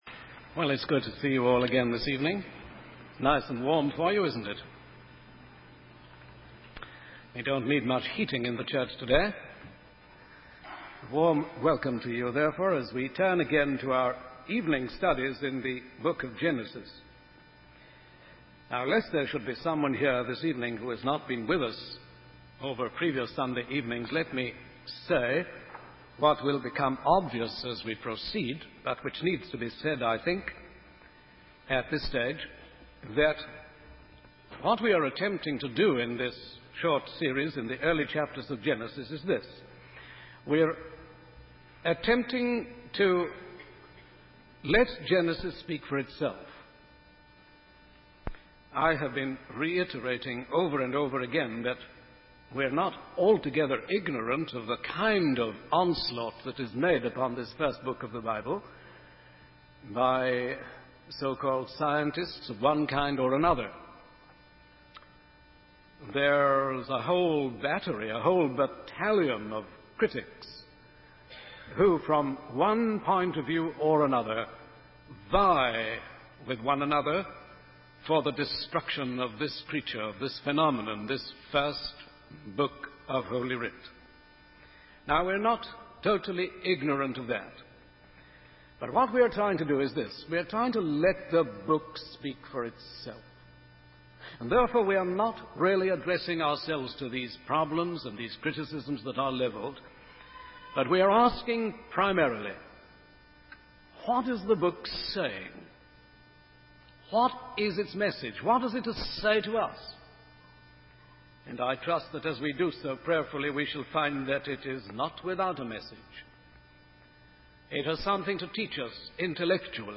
In this sermon, the speaker focuses on the story of Cain and Abel from Genesis 4. The main theme is the differences in worship between the two brothers, despite being children of the same parents and supposedly devoted to the same God.